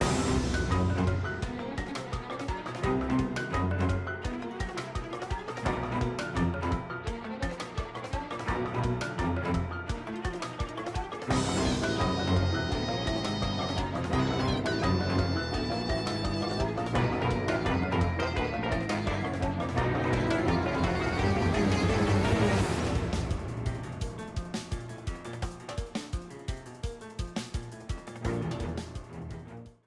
A purple streamer theme
Ripped from the game
clipped to 30 seconds and applied fade-out